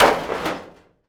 metal_sheet_impacts_11.wav